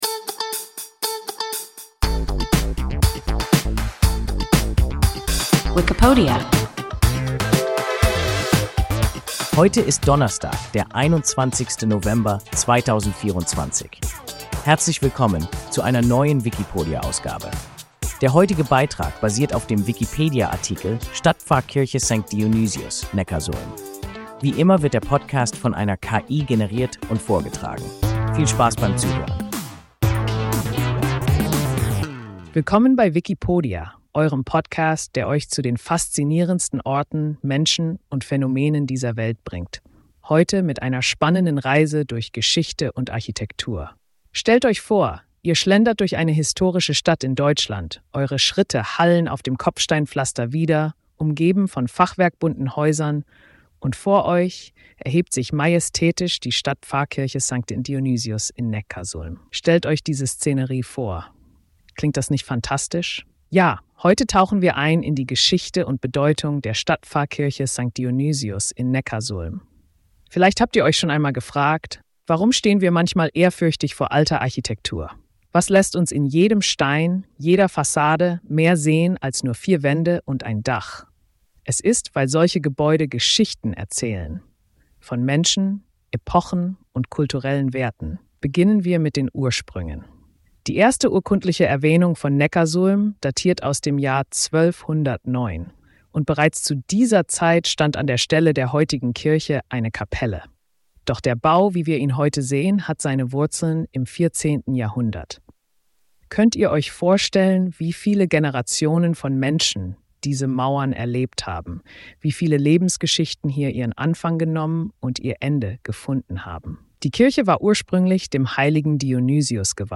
Stadtpfarrkirche St. Dionysius (Neckarsulm) – WIKIPODIA – ein KI Podcast